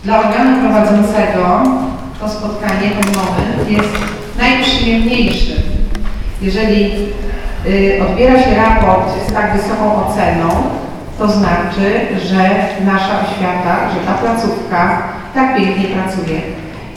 Wczoraj w Zespole Publicznych Szkół nr 1 w Żninie, miało miejsce wręczenie raportu z ewaluacji szkoły.
Swojego zadowolenia nie kryje także Aleksandra Nowakowska, wiceburmistrz Żnina.